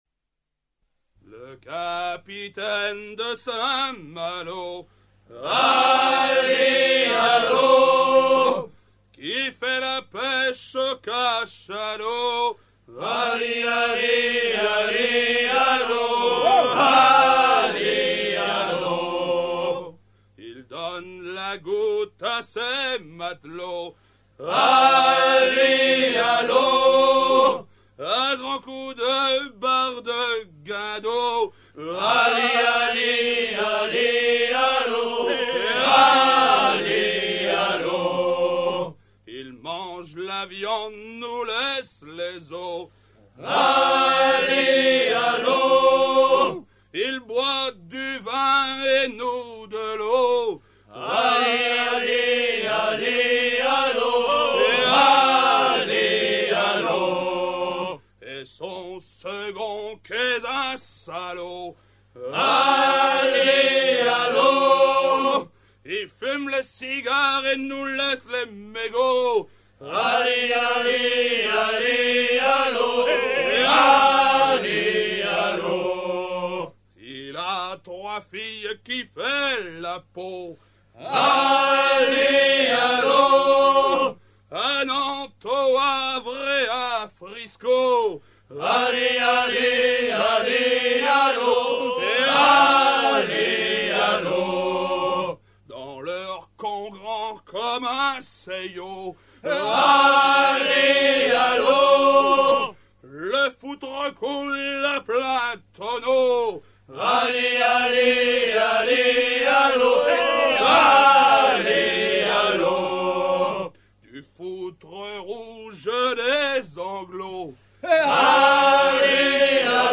à hisser main sur main
maritimes
Genre laisse
Pièce musicale éditée